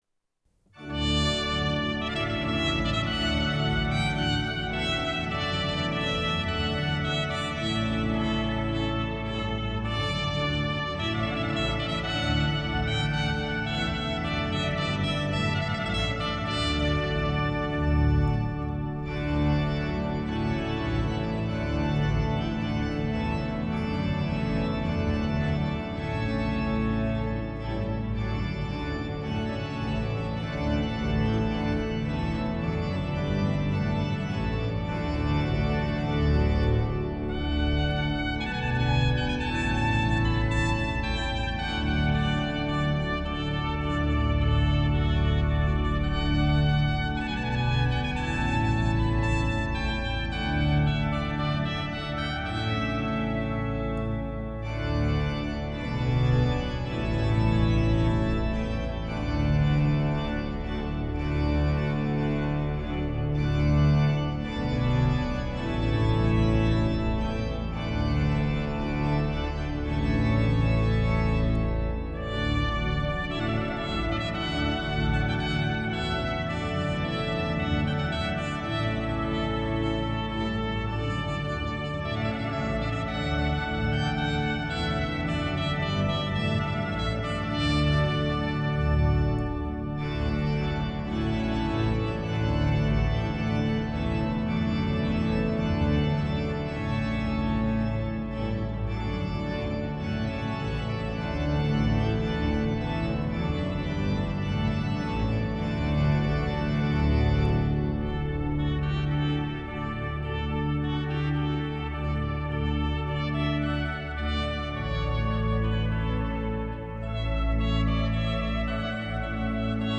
ORGANO y TROMPETA
Las más bellas obras para Trompeta y Órgano
grabadas en la Catedral Metropolitana de Valladolid y en otros lugares